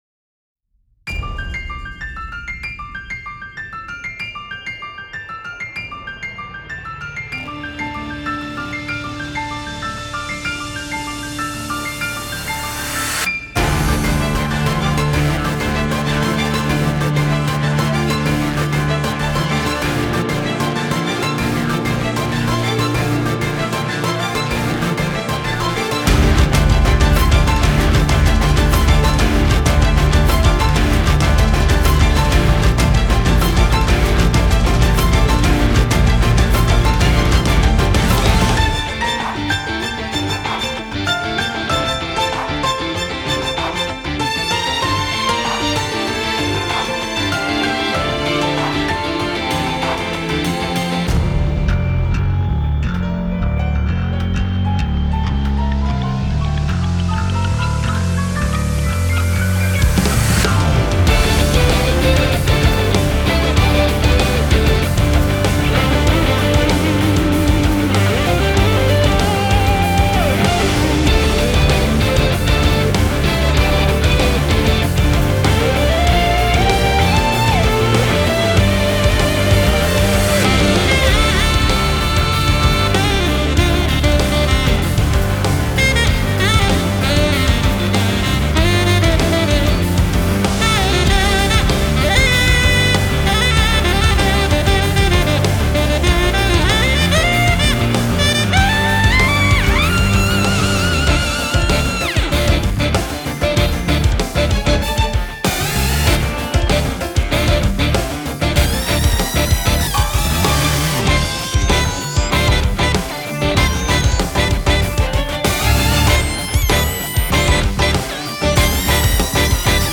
موسیقی اینسترومنتال موسیقی بی کلام